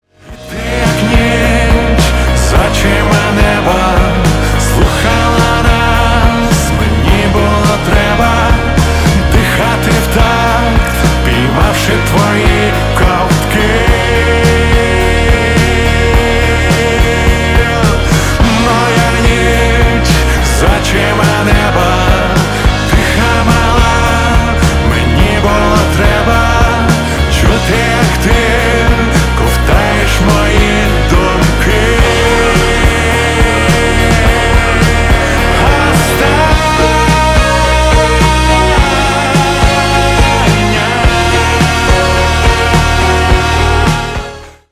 мужской вокал
Pop Rock
украинский рок
поп-рок